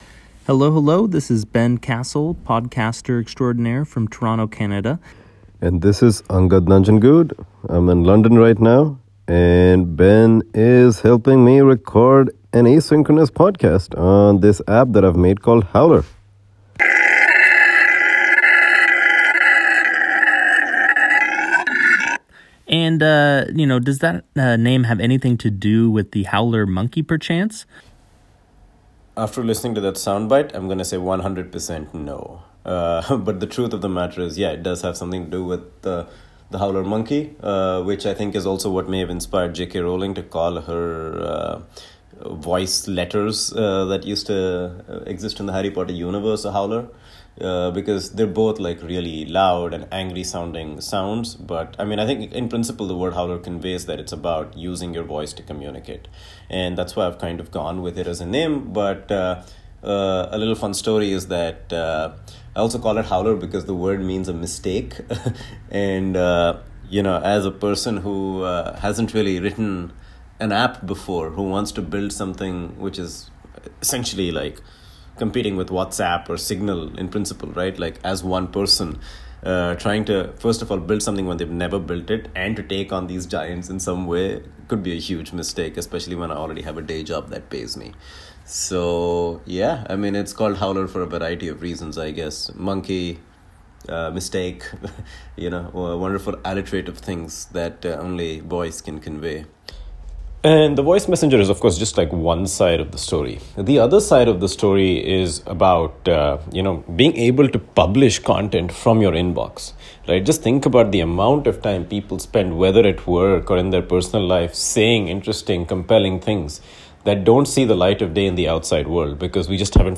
My eponymous idea, Howler®, builds on this; in-app personal voice messages are quickly converted into podcasts that sound like a synchronous conversation (